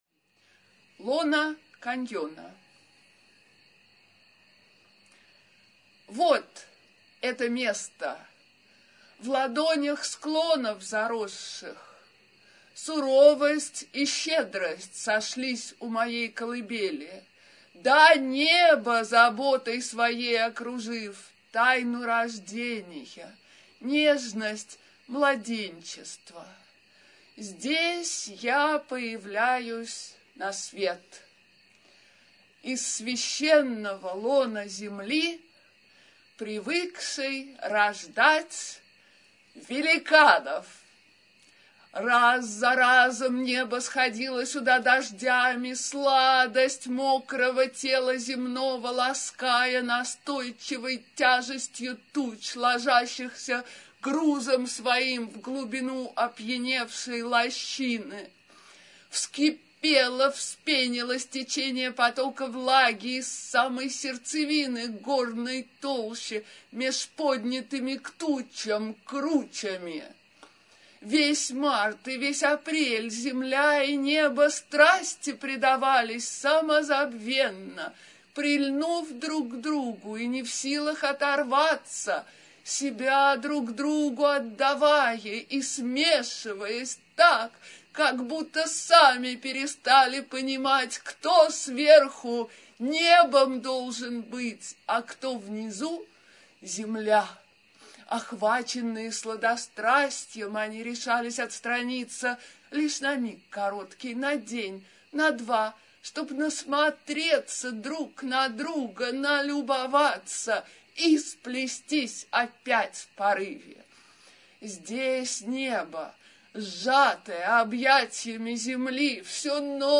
Авторское чтение: